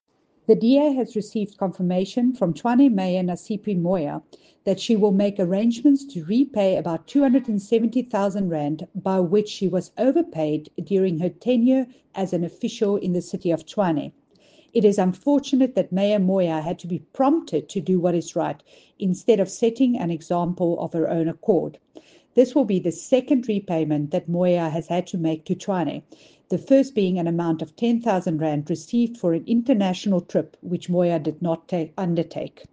English soundbite by Cllr Jacqui Uys